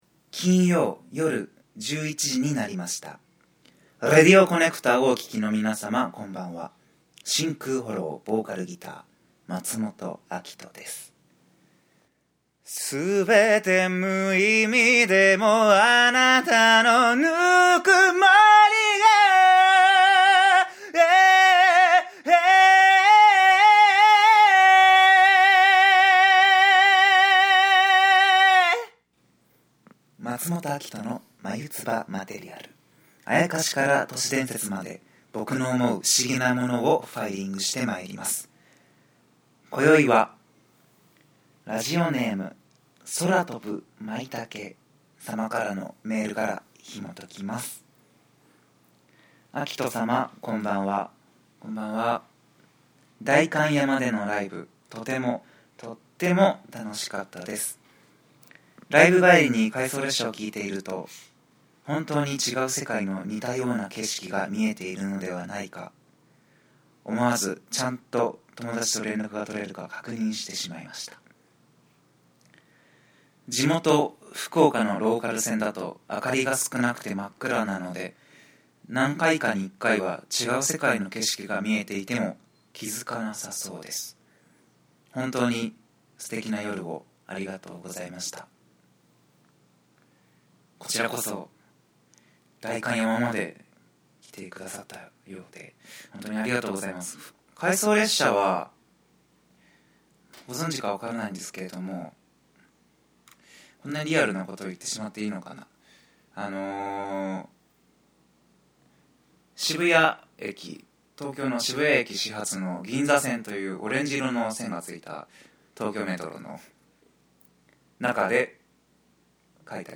今週の生歌のなんと艶やかな事！！